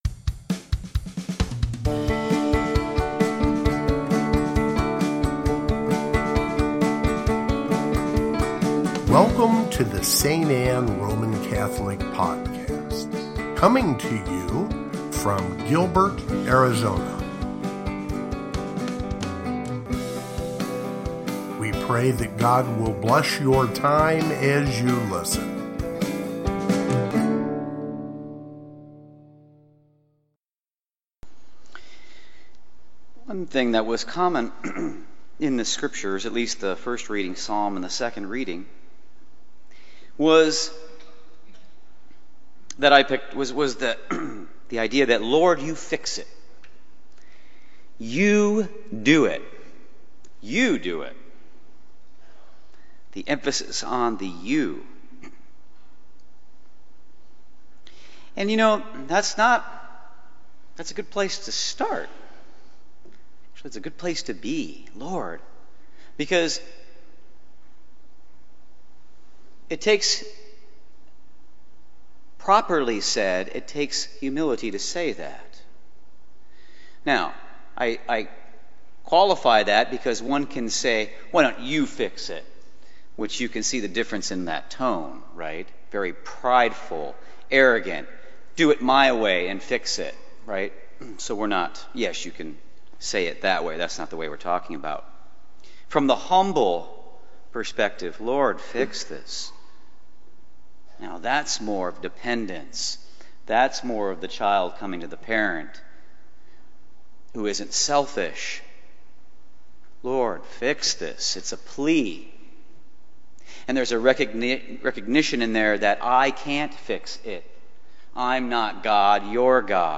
First Sunday of Advent (Homily) | St. Anne